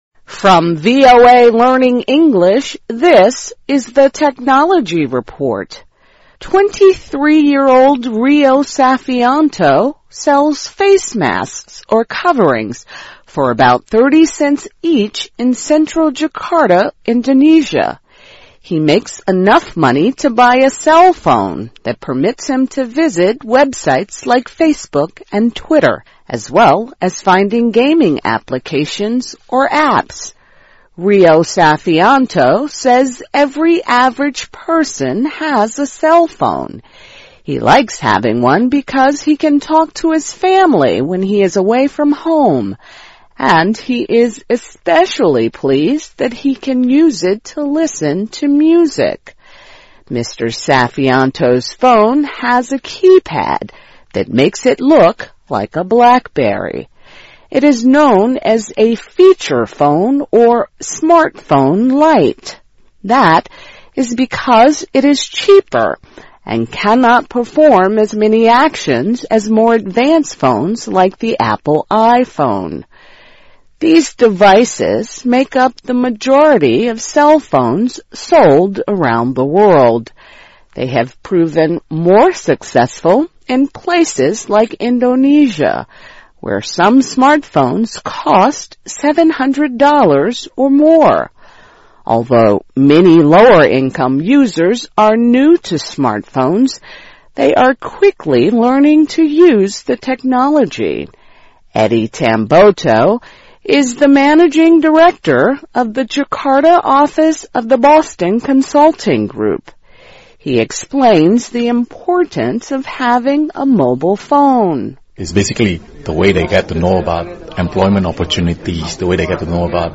VOA慢速英语2013 科技报道 - 印尼人使用智能手机上网 听力文件下载—在线英语听力室